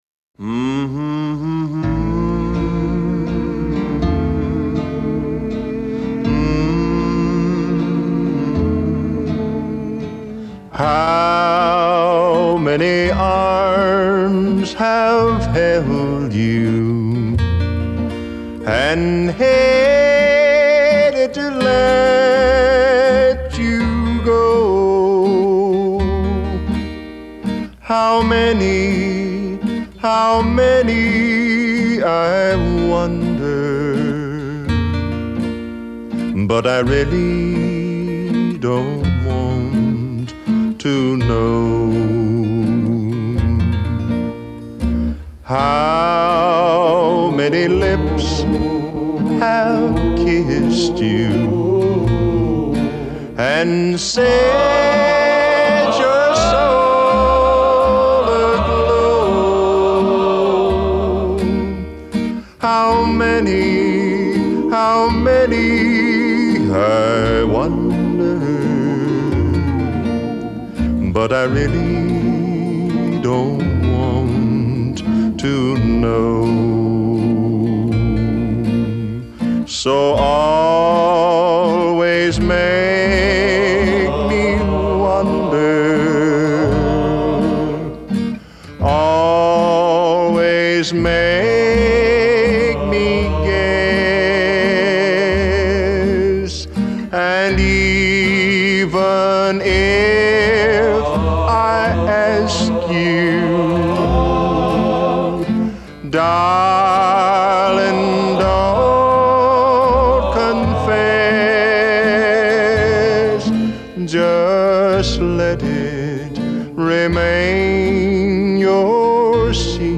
Man oh man, this guy was smooth.